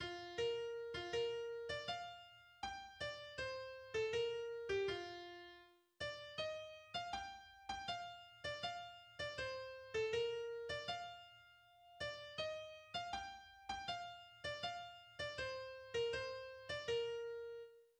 I bogen er den noteret i B-dur med tempobetegnelsen "Andantino".